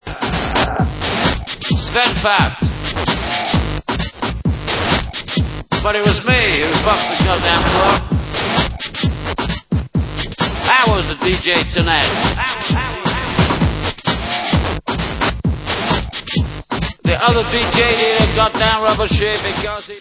Alternativní hudba